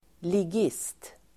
Ladda ner uttalet
ligist substantiv, hooligan, (gang member [US])Uttal: [lig'is:t] Böjningar: ligisten, ligisterSynonymer: bandit, buse, förbrytare, huligan, missdådare, skurkDefinition: medlem i en liga
ligist.mp3